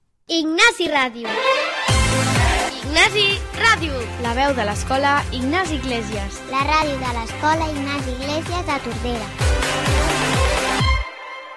Identificació de la ràdio